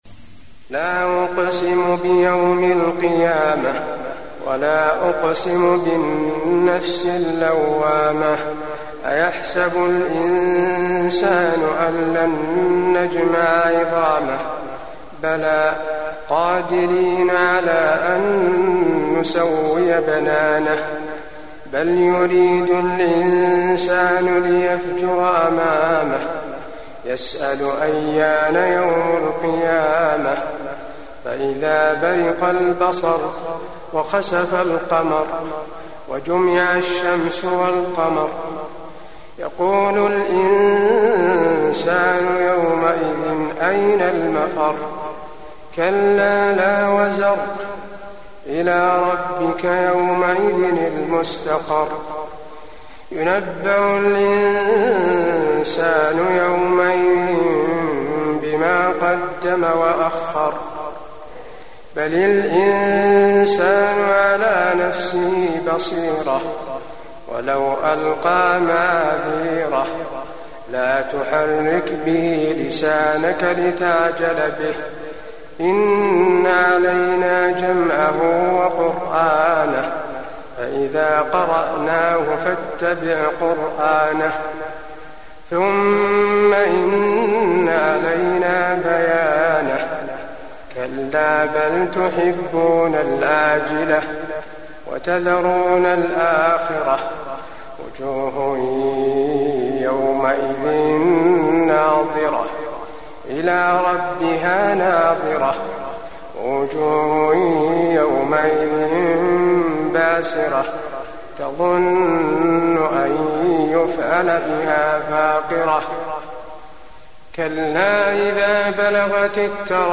المكان: المسجد النبوي القيامة